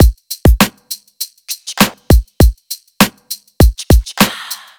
FK100BEAT1-R.wav